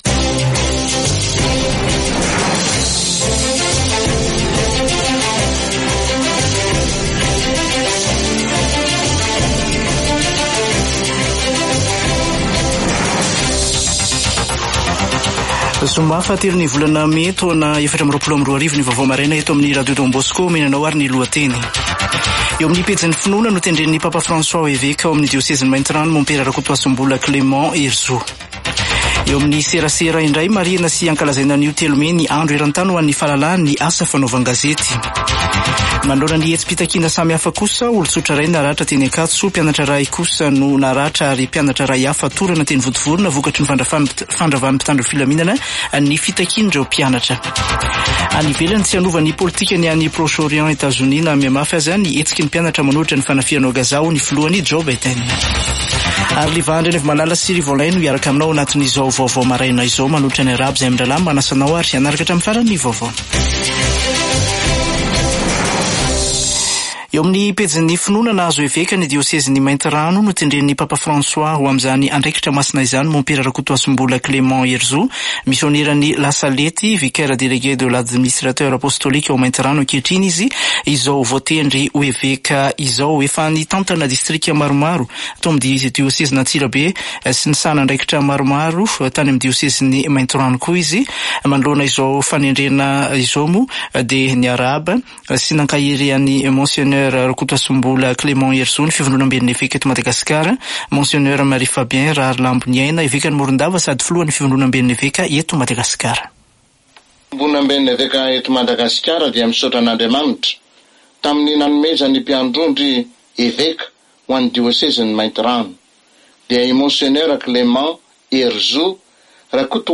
[Vaovao maraina] Zoma 3 mey 2024